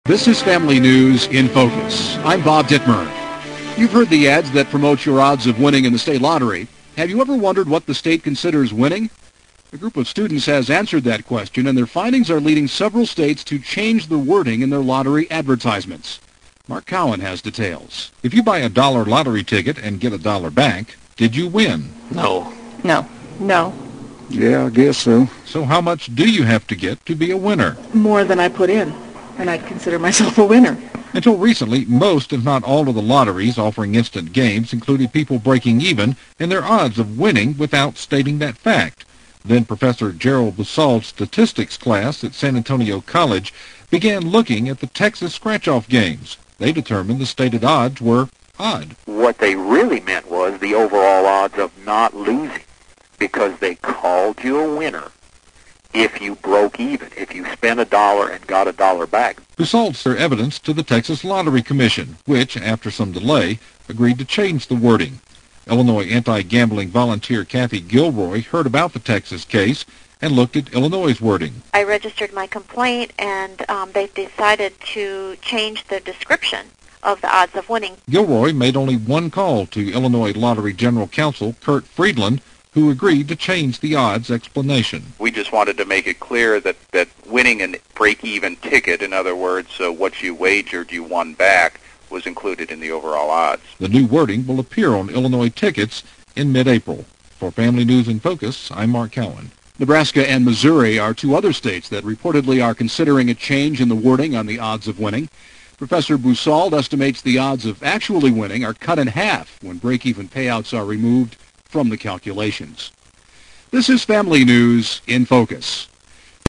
The Radio Interview